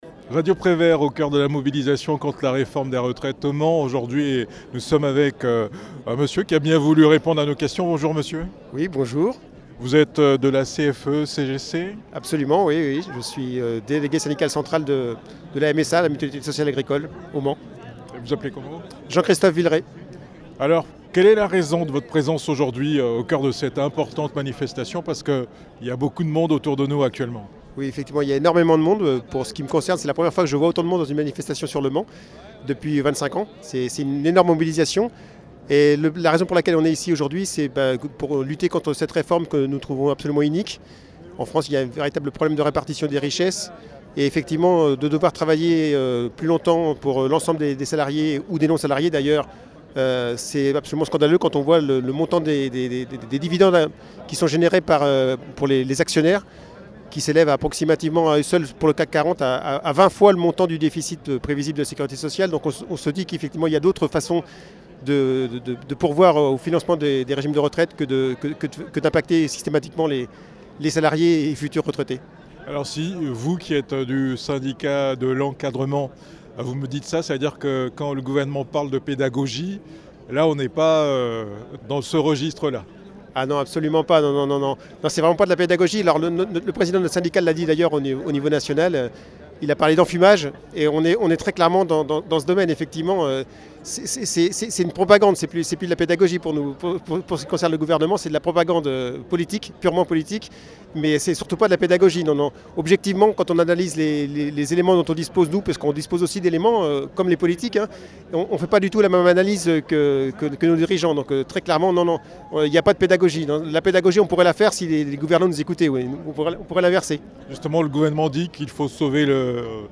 Manifestation contre la réforme des retraites au Mans